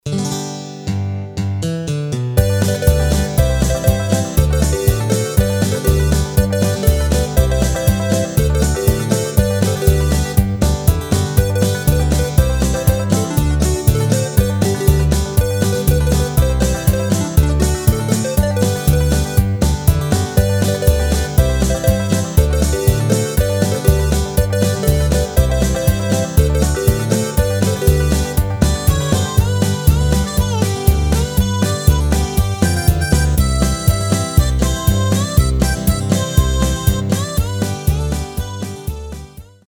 Rubrika: Folk, Country
Karaoke